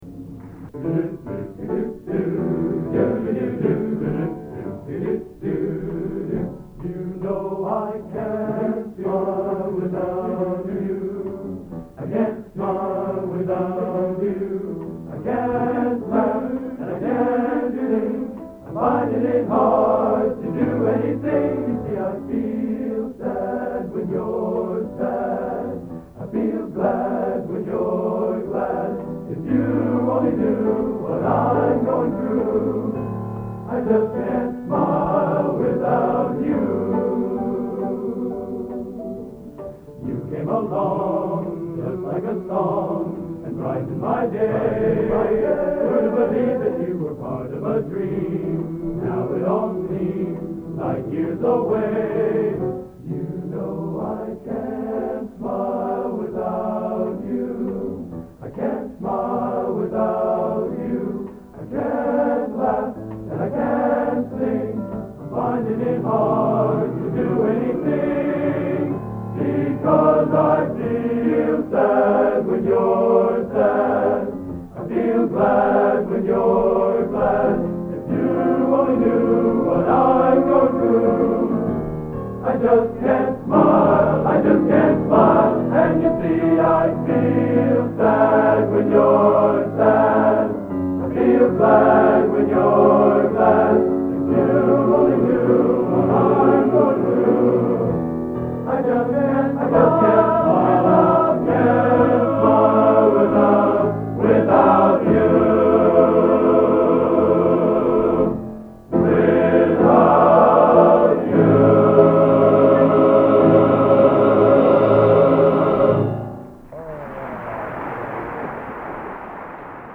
Location: West Lafayette, Indiana
Genre: Popular / Standards | Type: End of Season